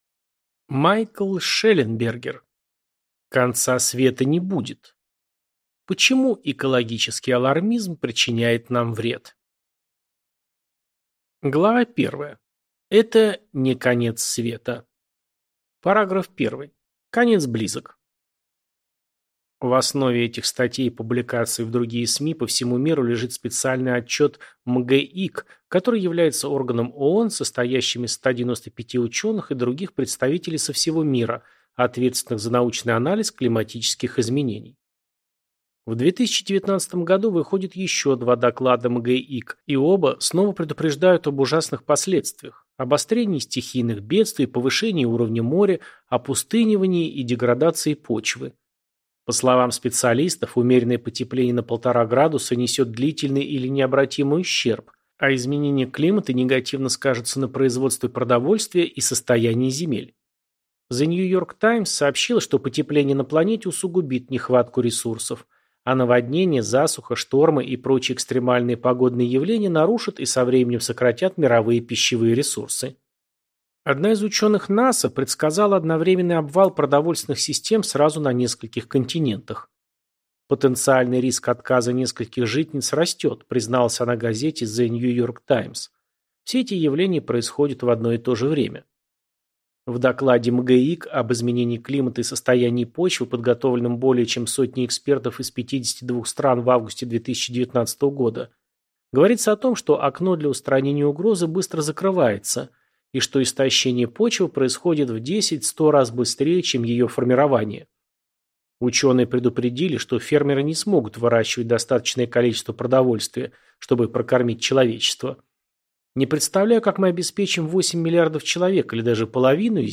Аудиокнига Конца света не будет. Почему экологический алармизм причиняет нам вред | Библиотека аудиокниг